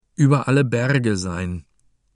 - słuchając nagrań native speakerów, nauczysz się prawidłowej wymowy
Przykładowy idiom